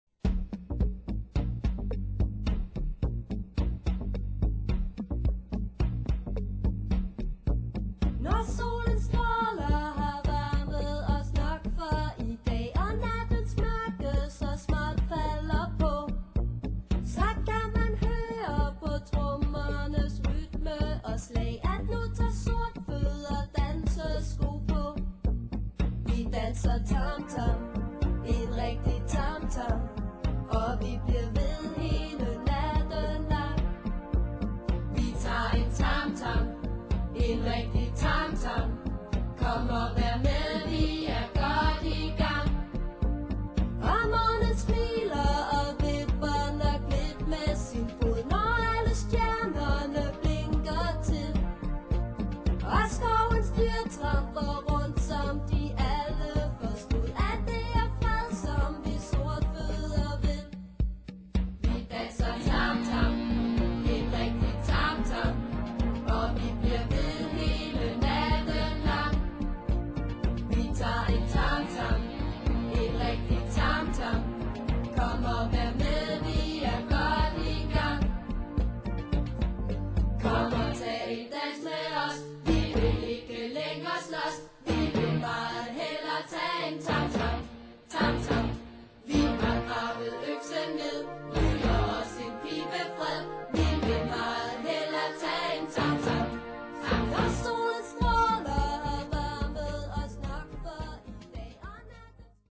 Indianerdans